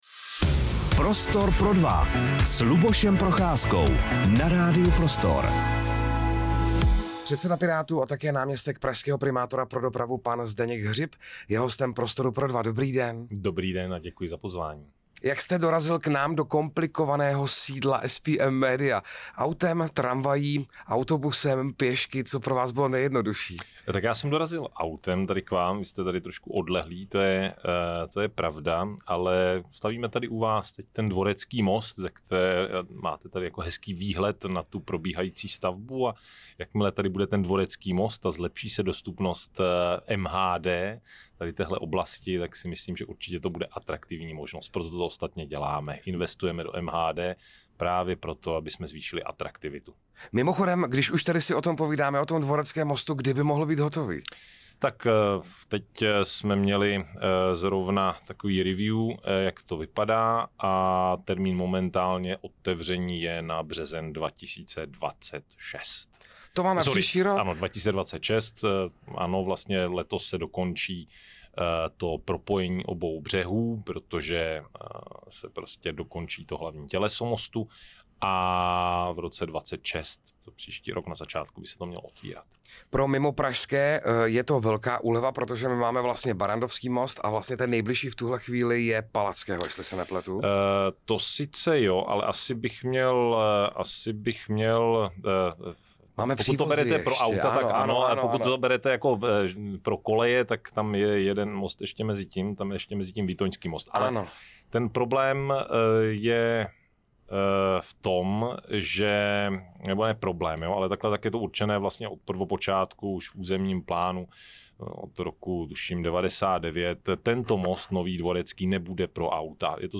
Rozhovor se Zdeňkem Hřibem | Radio Prostor
Hostem Prostoru pro dva byl předseda Pirátů a náměstek pražského primátora pro dopravu Zdeněk Hřib.